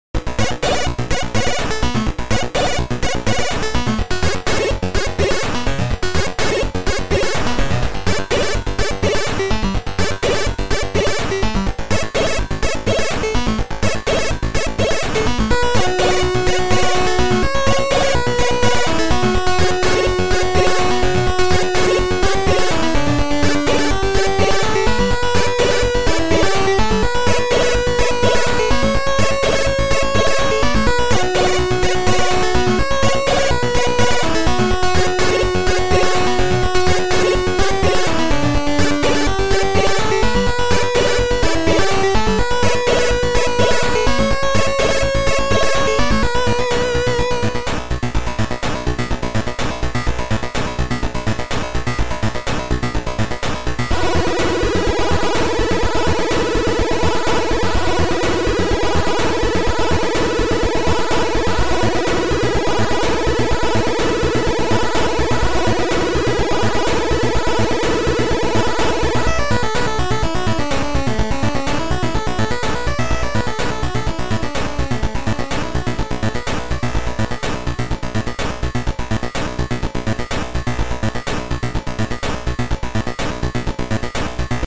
Sound Format: Noisetracker/Protracker
Chip Music Pack